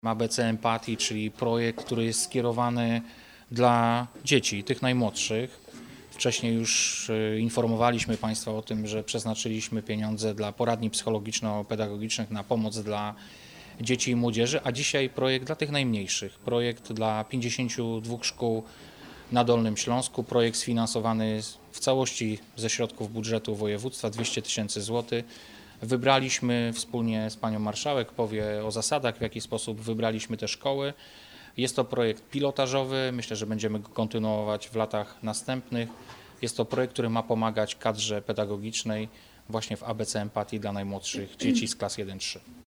Mówi Wojciech Bochnak, – Wicemarszałek Województwa Dolnośląskiego